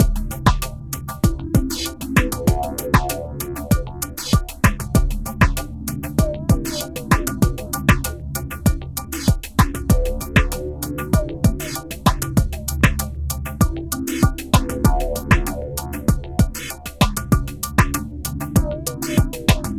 Downtempo 16.wav